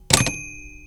typewriter ending bell
Mechanical typewriter typing sound effect free sound royalty free Sound Effects